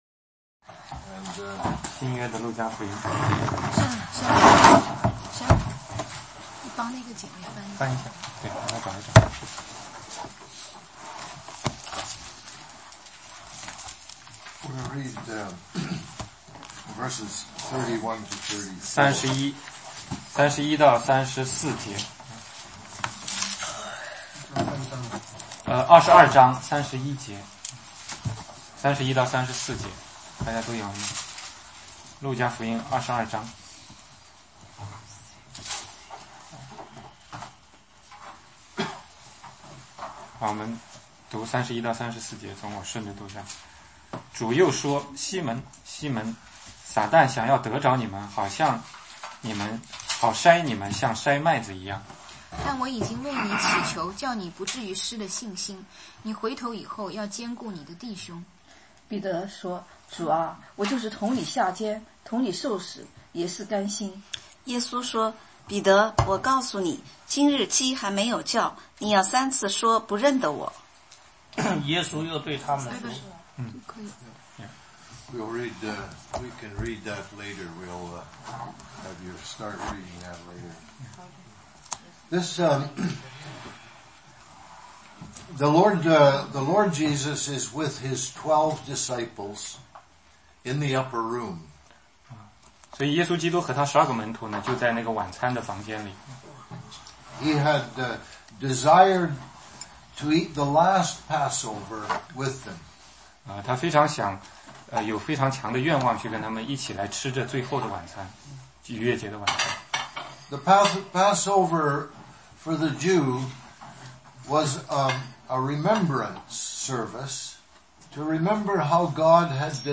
16街讲道录音 - 路加22-31,34